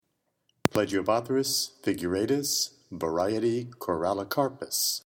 Pronunciation/Pronunciación:
Pla-gi-o-bó-thrys fi-gu-rà-tus var. co-ral-li-cár-pus